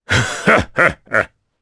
Kaulah-Vox_Happy2_jp.wav